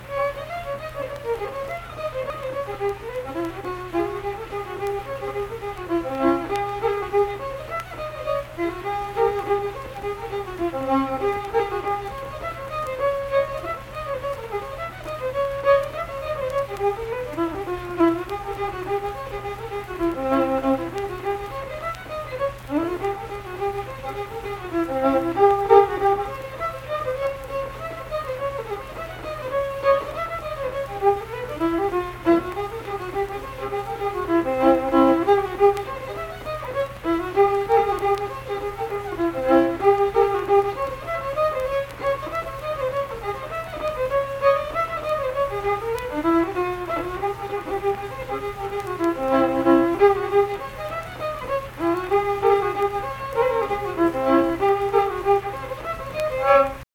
Unaccompanied fiddle music
Verse-refrain 2(2).
Instrumental Music
Fiddle
Saint Marys (W. Va.), Pleasants County (W. Va.)